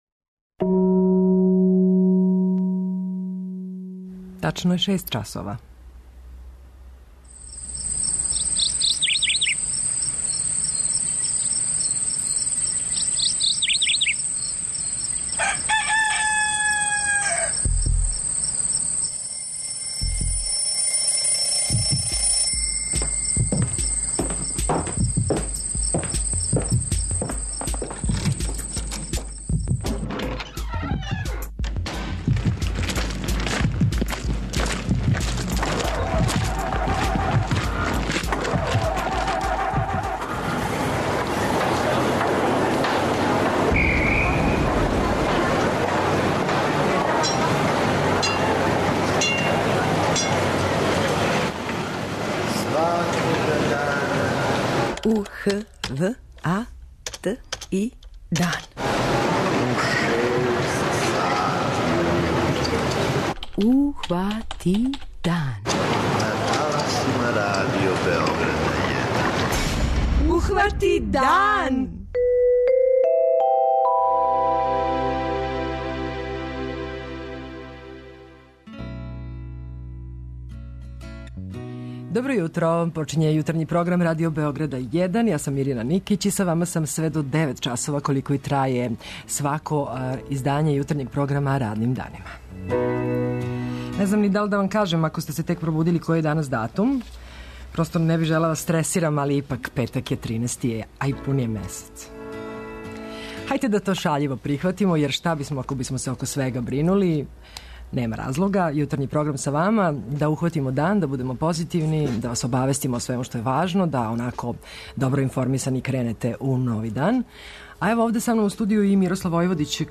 Репортер Јутарњег програма јавиће се са једне од београдских пијаца, а чућемо колико је богата понуда и какве су цене сезонског воћа и поврћа.